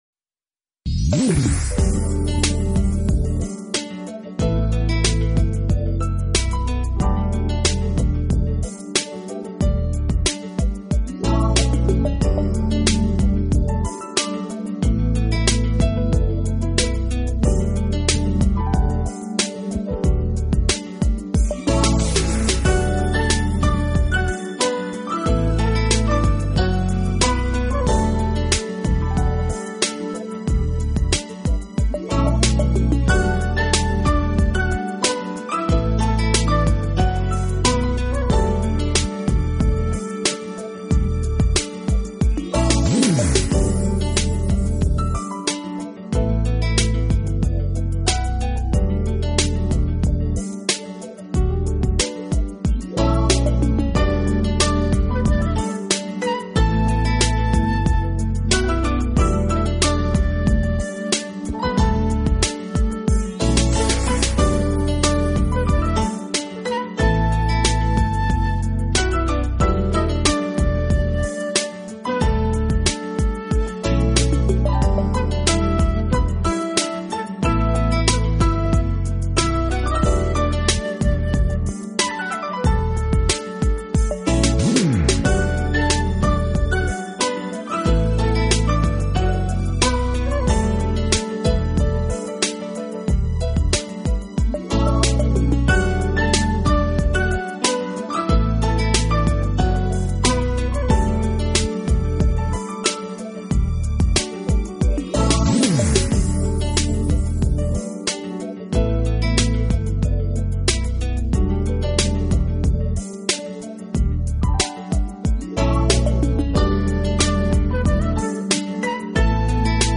jazz mood; he never leaves you wanting.